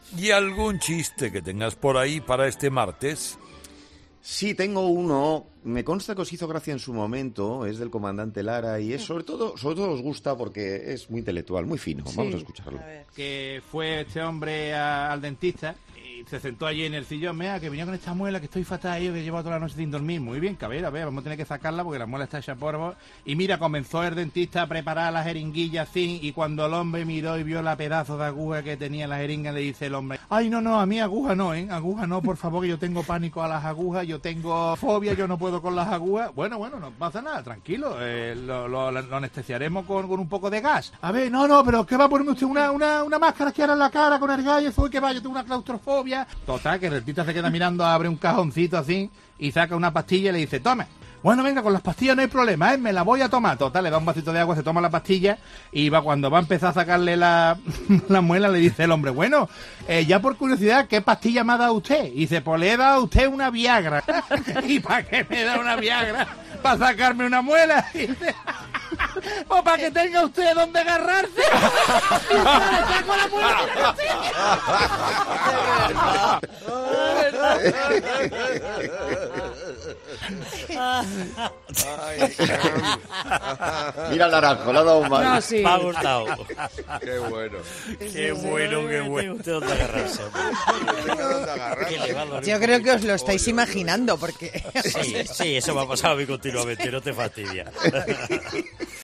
El programa ha detenido su ritmo habitual hasta que colaboradores y director se han recompuesto tras reír por el chiste del Comandante Lara